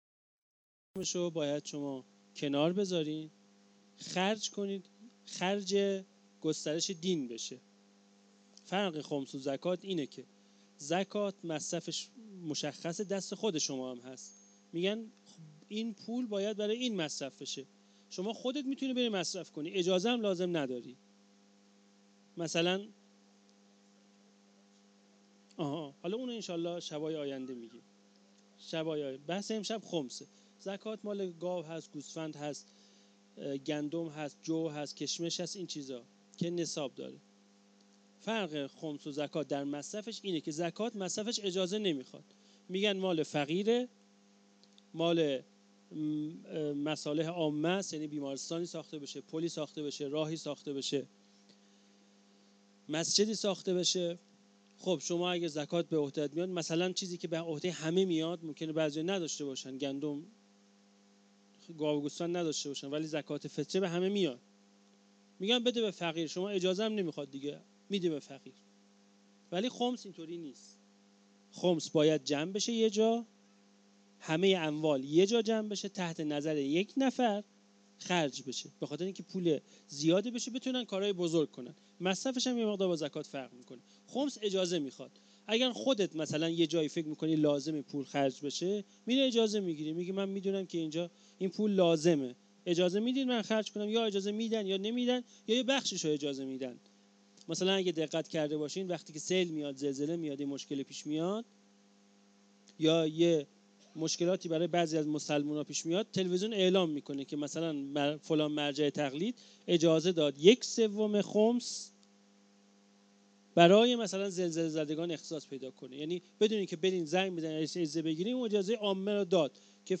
سخنرانی شب سوم محرم93
Sokhanrani-Shabe-03-moharram93.mp3